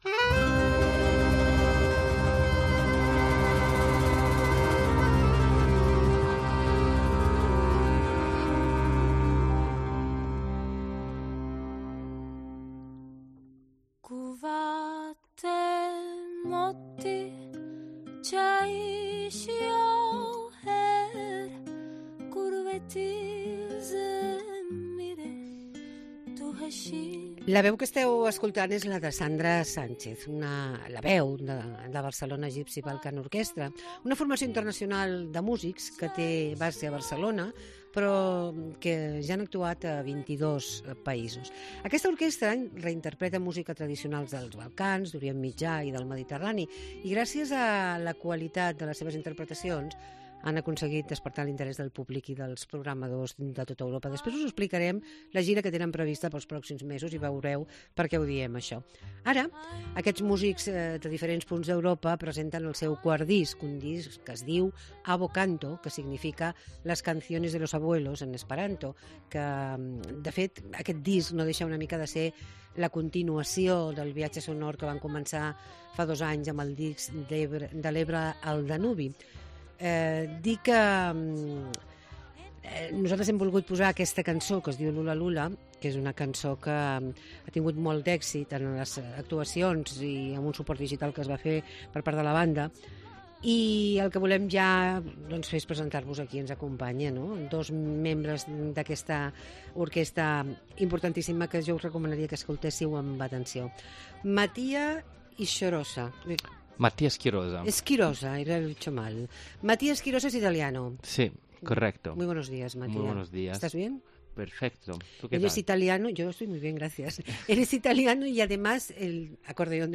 Entrevistem a la Barcelona Gipsy Balkan Orquestra que fan concert a Barcelona